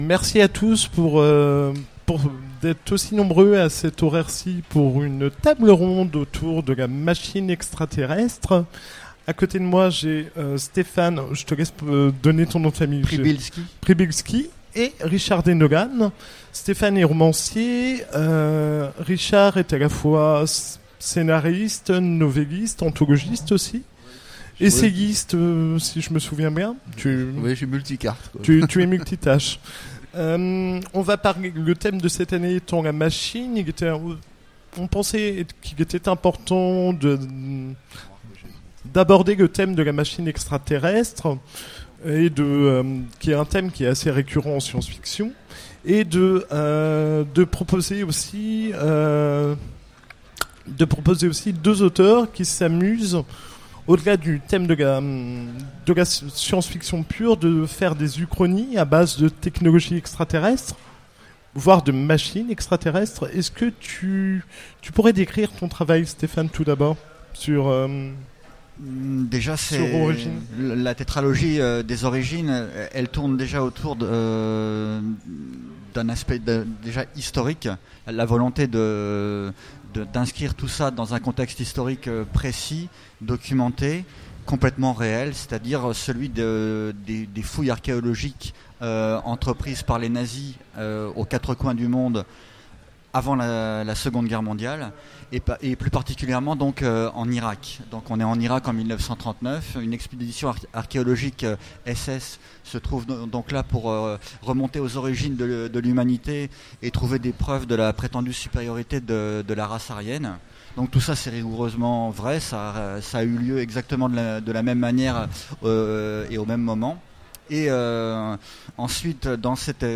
Utopiales 2016 : Conférence Les machines extra-terrestres